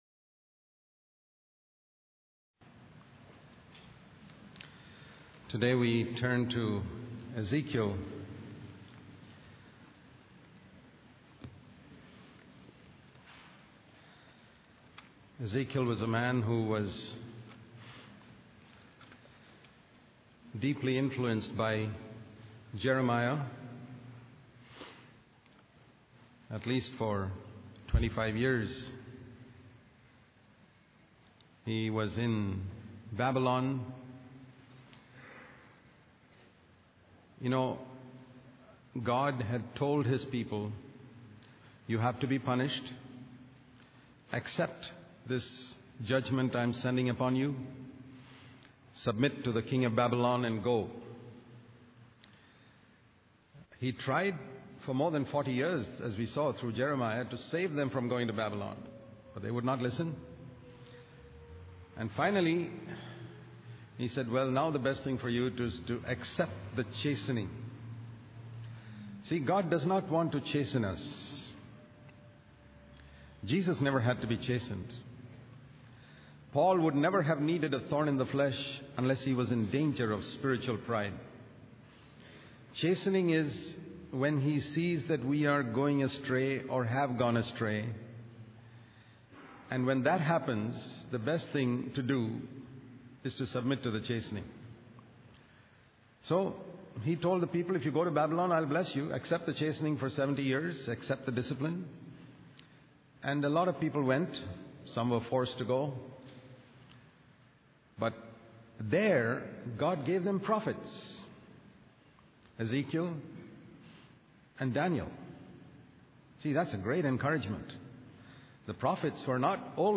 In this sermon, the preacher emphasizes the importance of serving God regardless of our feelings or desires. He describes a vision in which four living beings with different faces symbolize strength, service, heavenly nature, and humanity.